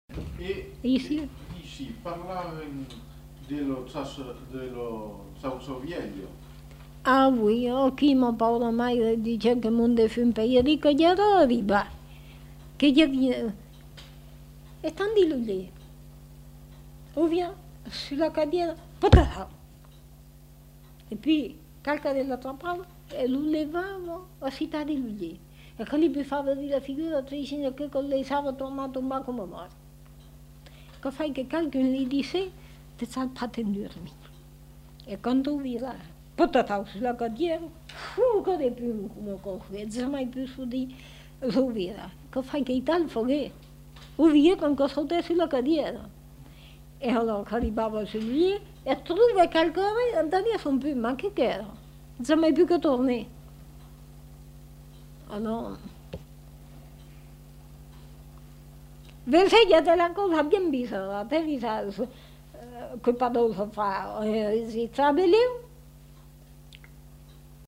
Aire culturelle : Périgord
Lieu : La Chapelle-Aubareil
Genre : conte-légende-récit
Type de voix : voix de femme
Production du son : parlé